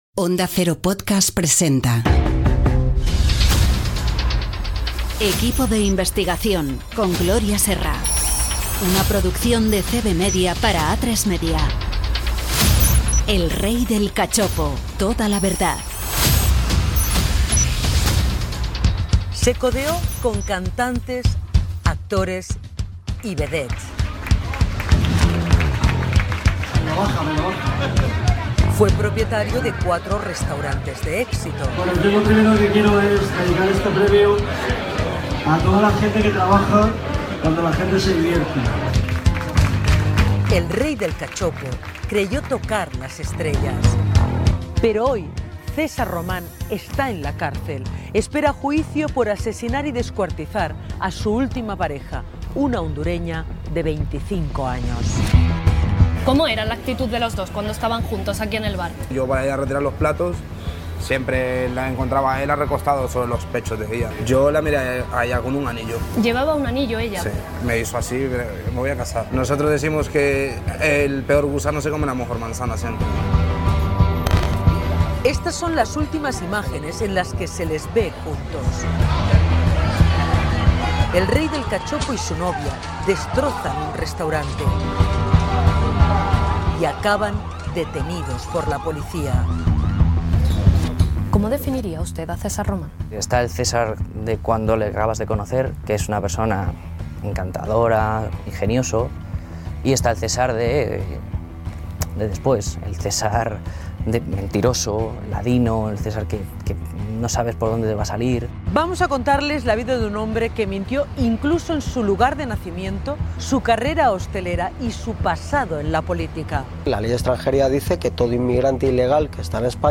Indicatiu d'Onda Cero Podcast, careta del programa, espai dedicat al cas de César Román "El rey del cachopo. Presentació del cas amb diversos testimonis
Informatiu
Podcast fet amb la banda sonora del programa ‘Equipo de investigación’ d'Atresmedia TV, emès el 16 de novembre del 2018 per La Sexta.